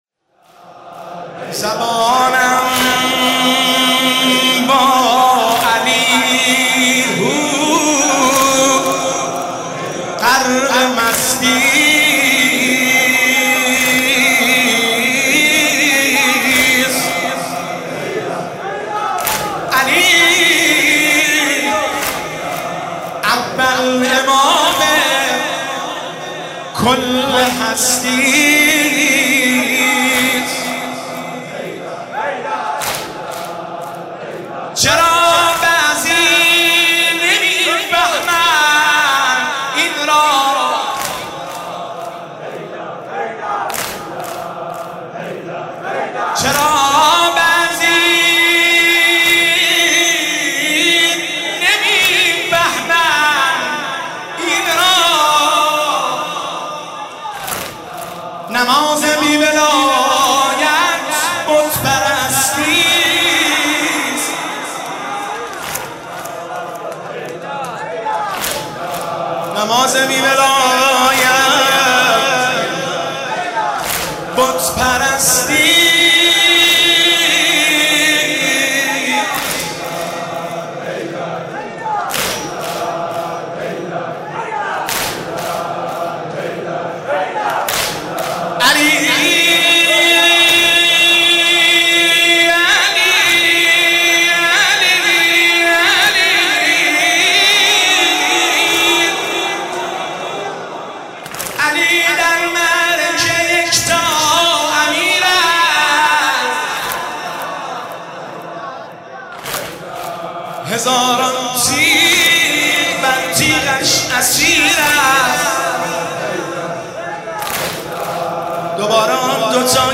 محل اجرا بین الحرمین تهران
۵ – ذکر لب پهلوونا یا علی مولا مدد – واحد